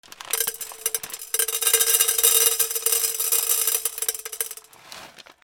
ポップコーン コーンをなべに入れる